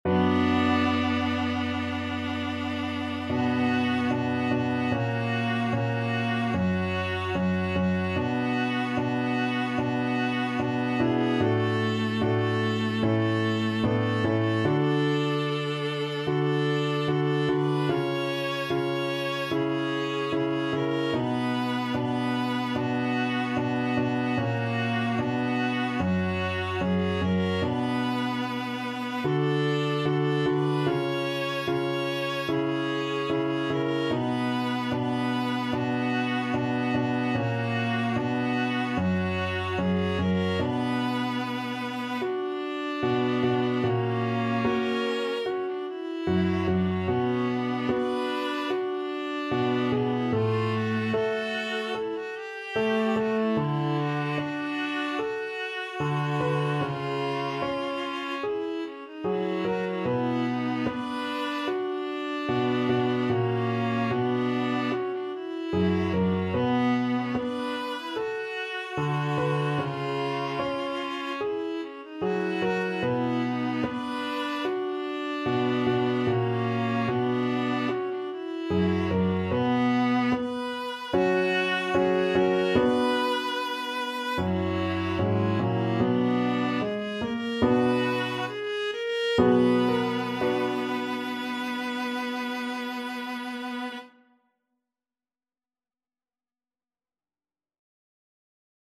Allegretto = c. 74
2/4 (View more 2/4 Music)
Viola Duet  (View more Easy Viola Duet Music)
Classical (View more Classical Viola Duet Music)